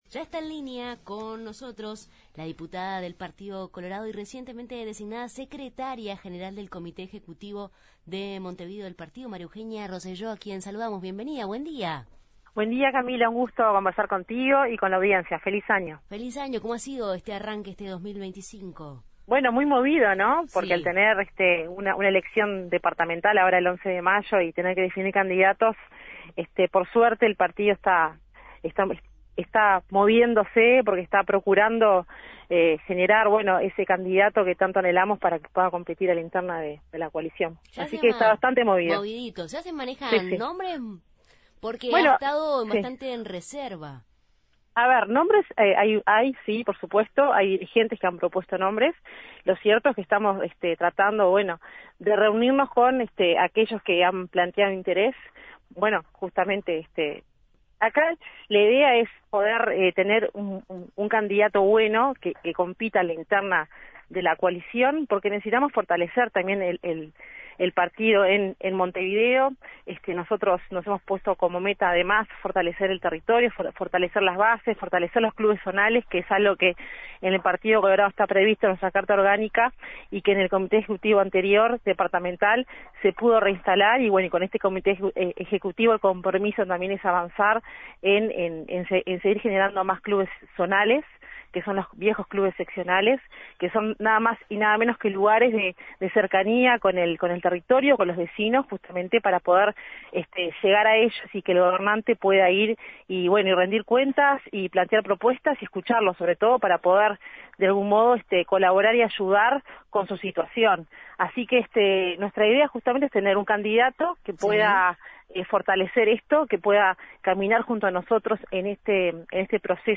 En Justos y pecadores entrevistamos a la diputada y secretaria general del Comité Ejecutivo Departamental del Partido Colorado María Eugenia Roselló